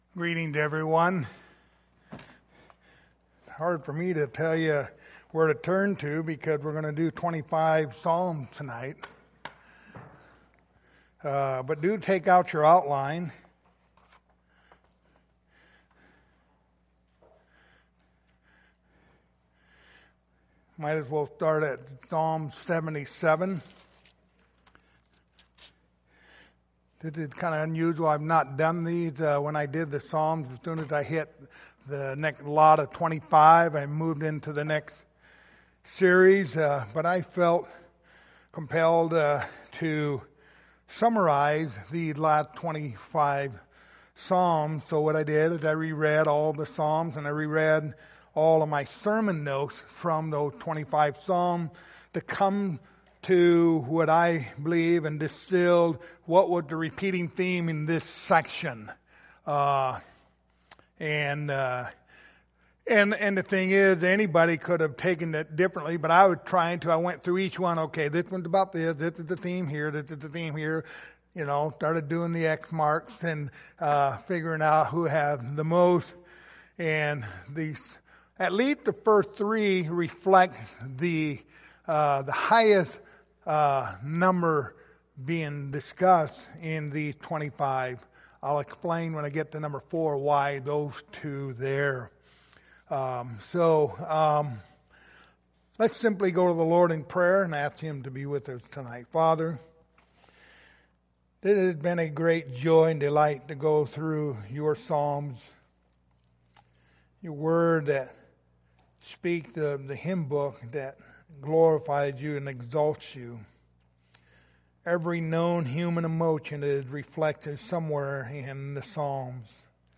psalms 76 Service Type: Sunday Evening Topics